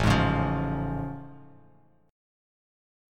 A+M7 chord